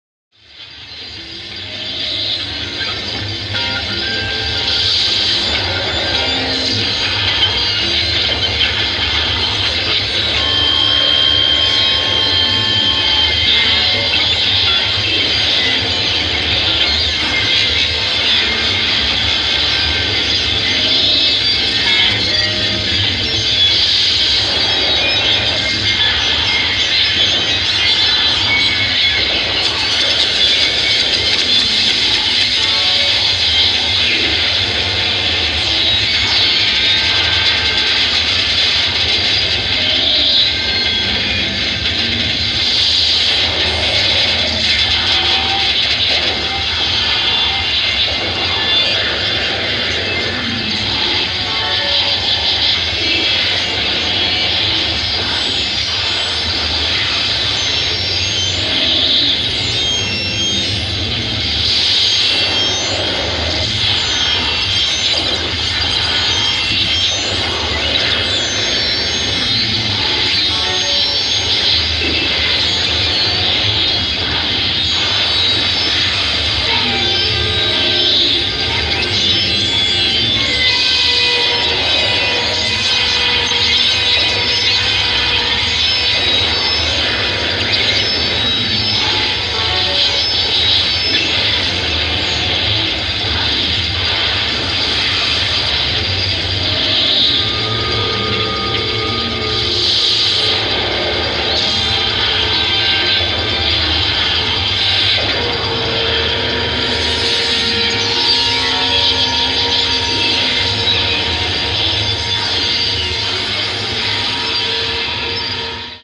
Melting cinematic sounds.